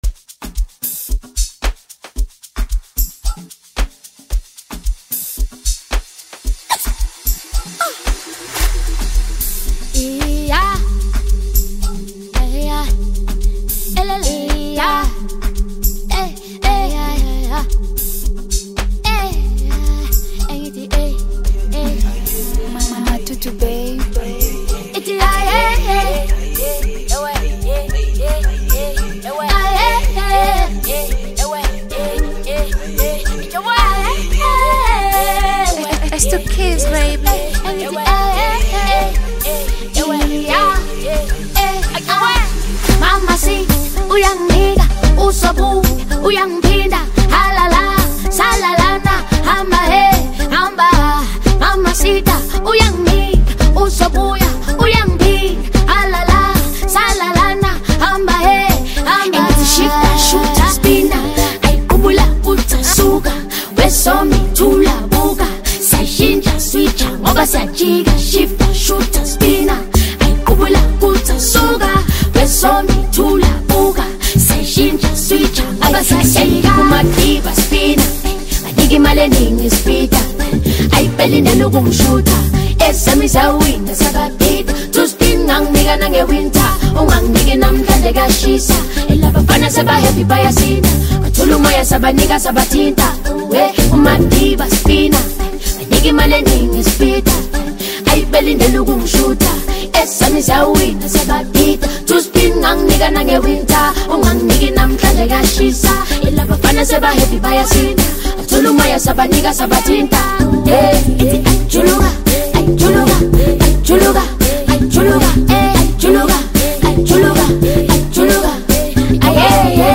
Bongo Flava